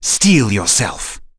Zafir-Vox_Skill1.wav